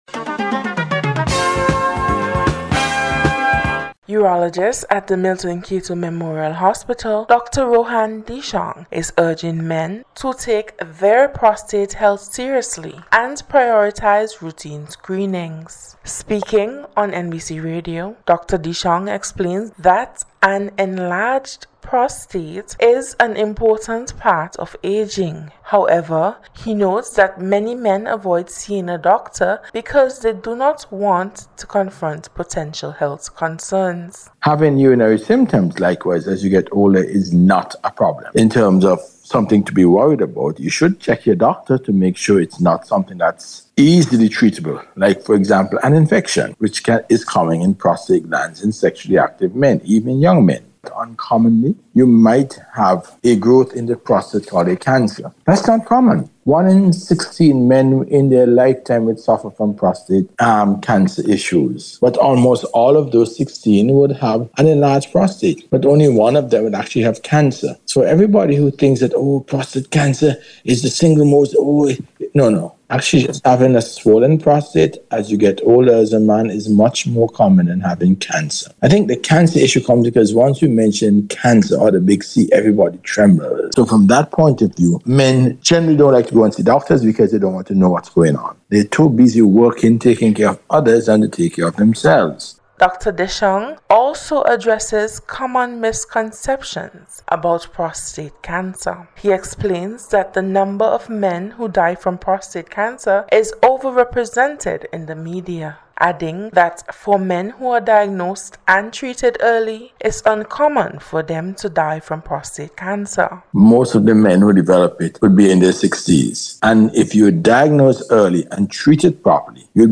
PROSTATE-HEALTH-CHECKS-REPORT.mp3